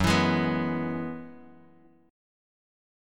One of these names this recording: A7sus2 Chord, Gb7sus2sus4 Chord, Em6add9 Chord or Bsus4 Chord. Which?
Gb7sus2sus4 Chord